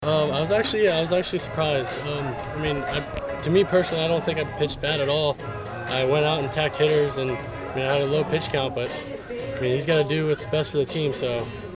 Sound from the Game